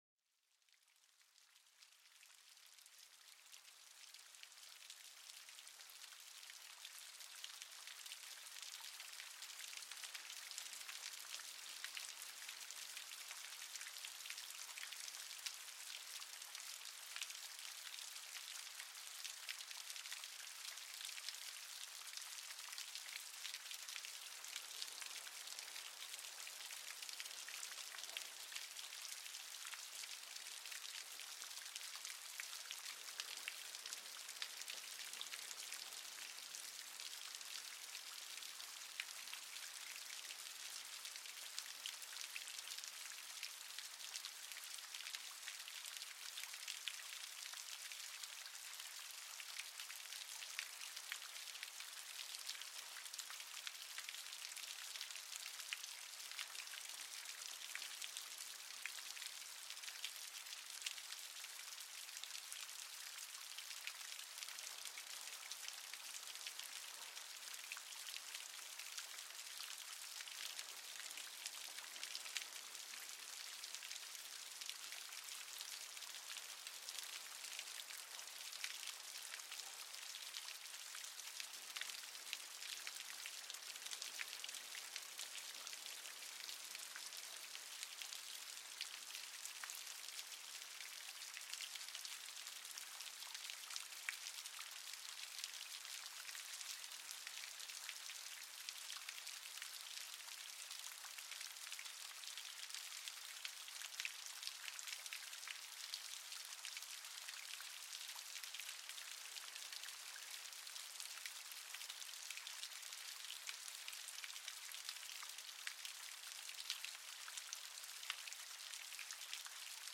En este episodio, déjate llevar por el sonido melódico de las gotas de lluvia, un verdadero concierto natural que calma la mente y revitaliza el alma. Descubre cómo este suave susurro puede mejorar la concentración y la relajación profunda, transportándote a un mundo de serenidad absoluta.